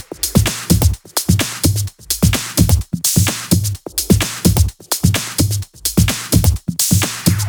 VFH1 128BPM Big Tee Kit 3.wav